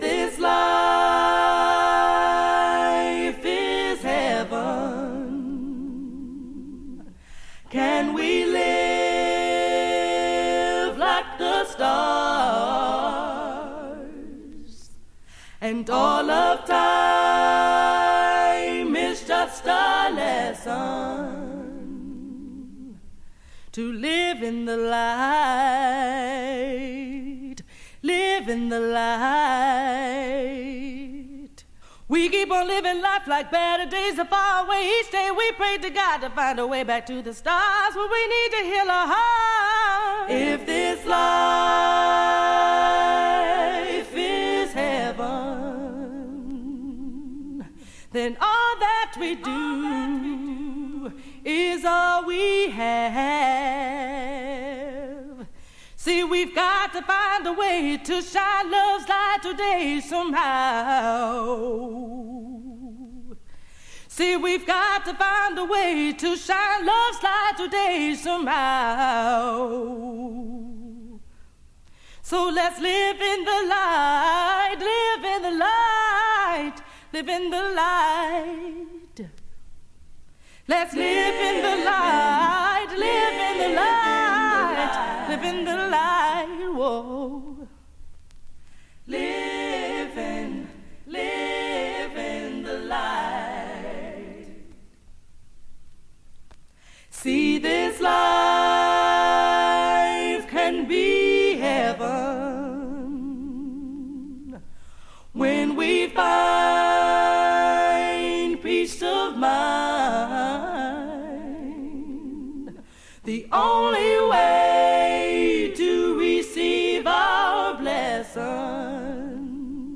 70年代のフリーフォームなブラックジャズを受け継ぐ